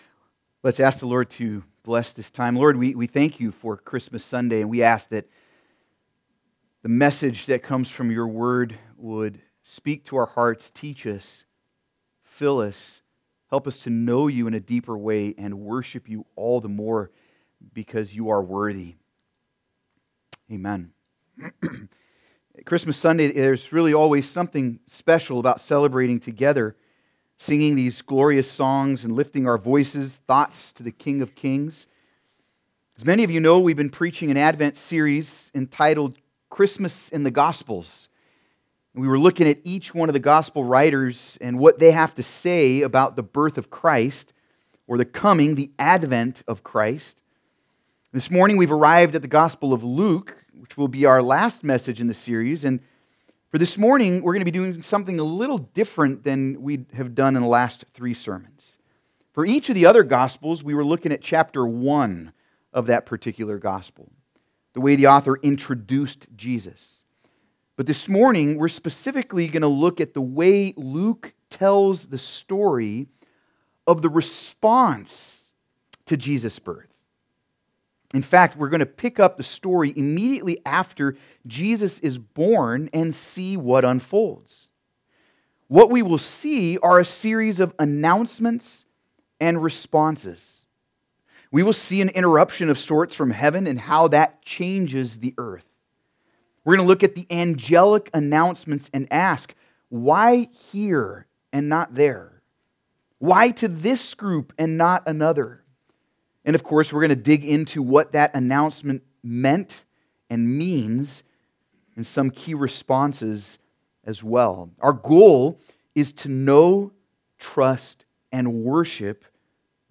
Passage: Luke 2:8-20 Service Type: Sunday Service